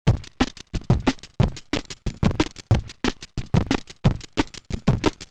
Here’s some direct audio examples of sliced WAV imports. DigiPRO sample playback on the Monomachine becomes way cleaner and more usable when we bypass slot normalisation and keep the original gain structure intact across a loop or single hit.
(1 drum hits, 2 break, 3 303, 4 break)